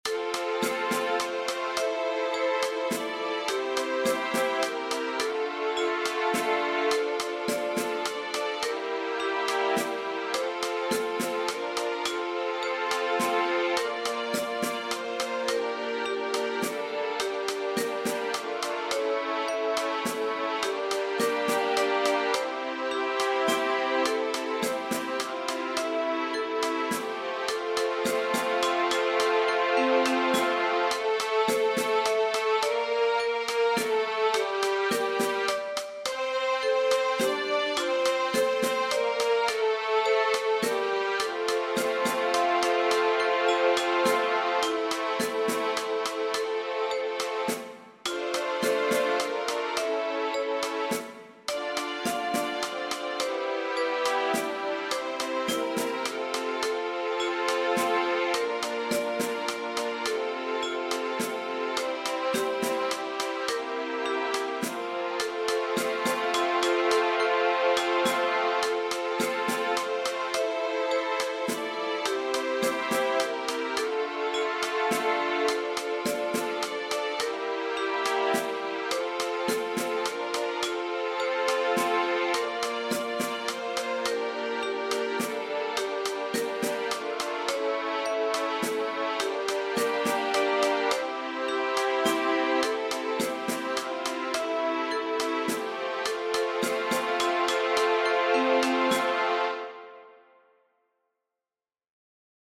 • Catégorie : Chants de Méditation